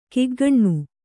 ♪ kiggaṇṇu